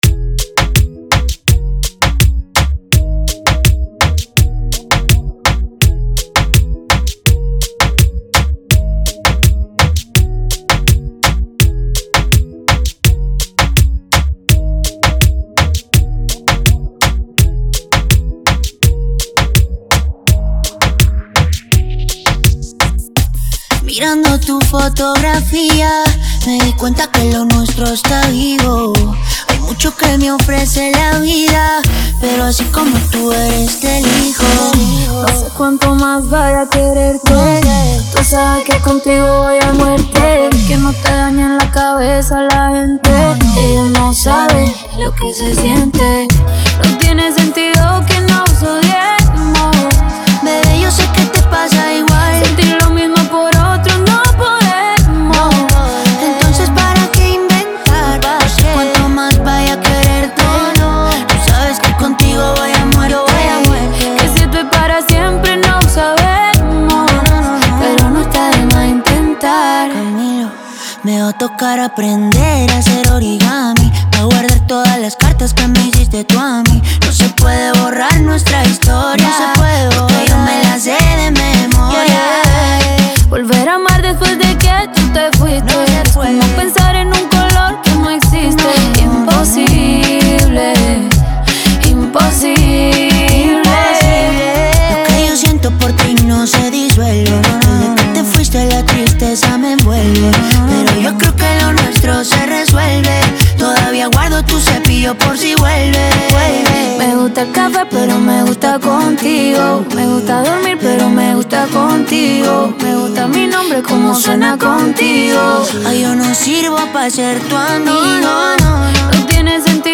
Genre: Reggaeton.